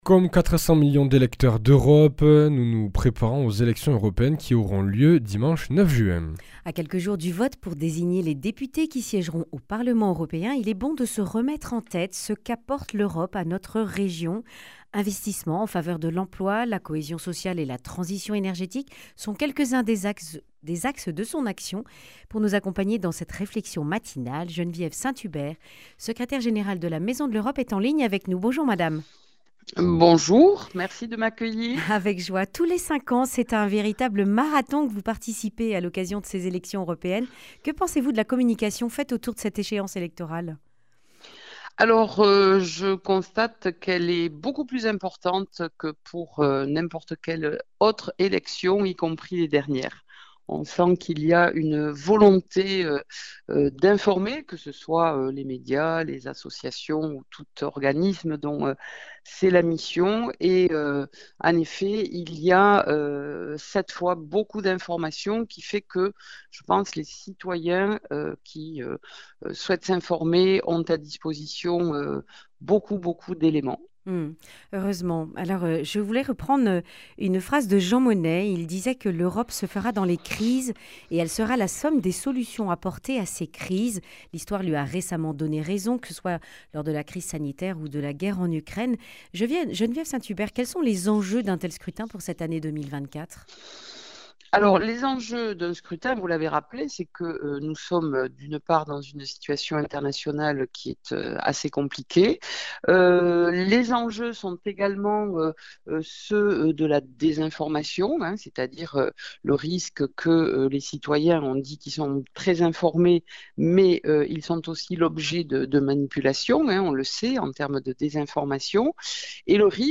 Accueil \ Emissions \ Information \ Régionale \ Le grand entretien \ Qu’apporte l’Europe à la région Occitanie ?